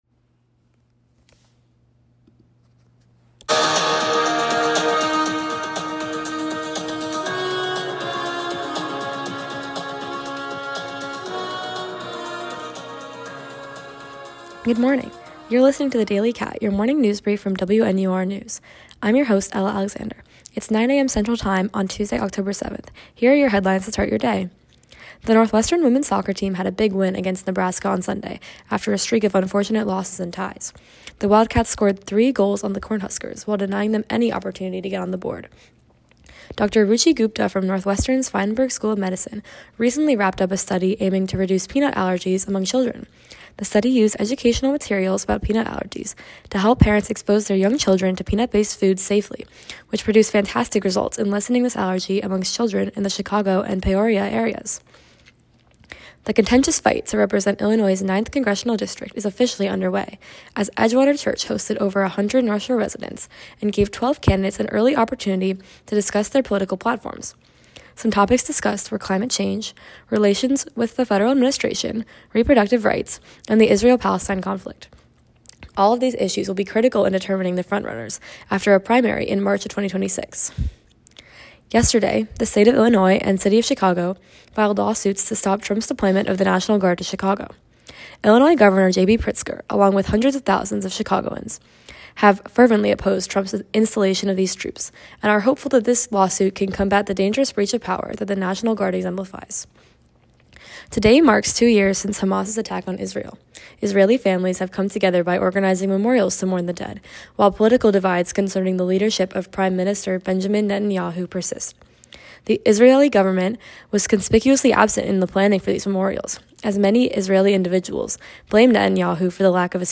October 7, 2025: Northwestern women’s soccer, Illinois 9th Congressional District election, federal judge blocks Trump Administration’s plan to send National Guard to Chicago, 2 years since Hamas attacks. WNUR News broadcasts live at 6 pm CST on Mondays, Wednesdays, and Fridays on WNUR 89.3 FM.